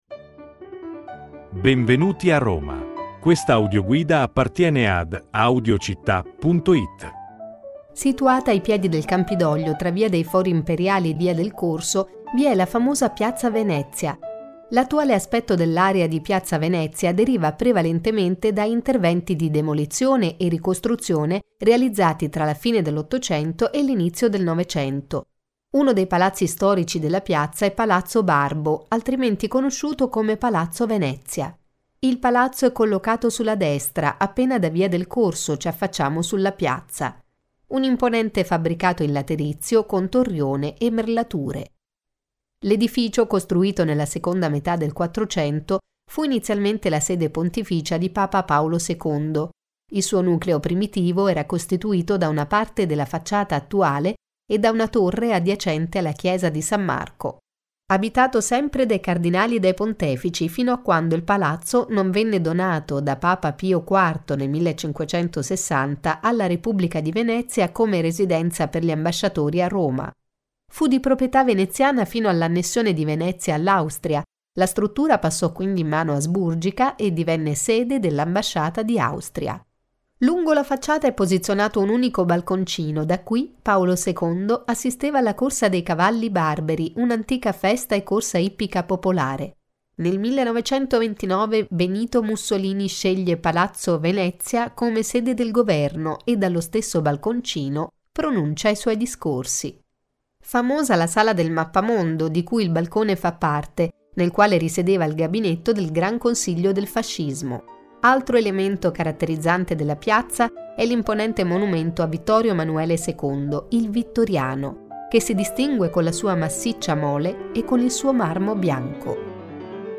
Audioguida: Piazza Venezia, Guida turistica in formato audio pronta da ascoltare